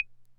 Wood Block.wav